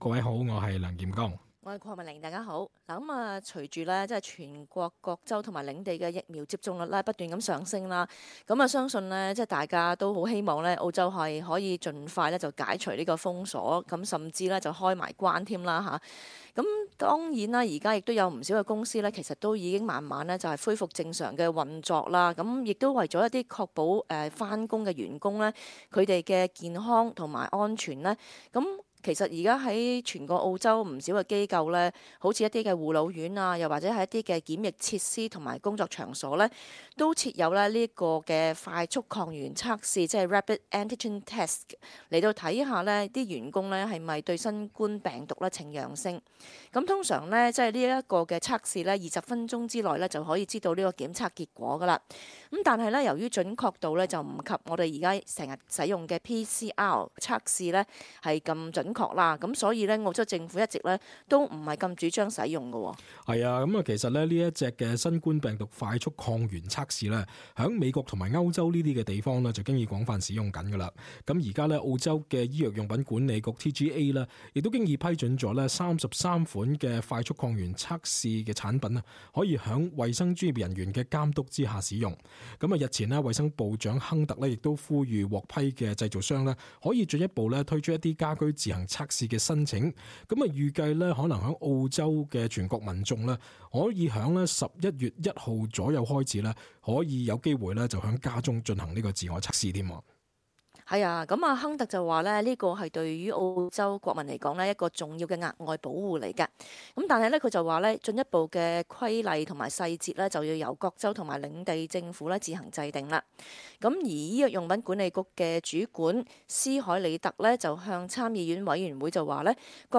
talkback_-_upload_sept_30.mp3